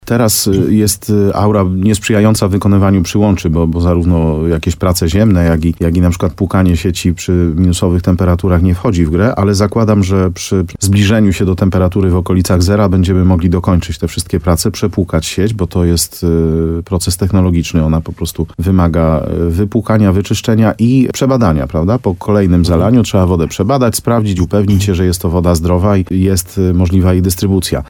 Wszystko zależy od tego, czy ocieplenie będzie chwilowe, czy mrozy jeszcze wrócą – mówi wójt Łużnej Mariusz Tarsa.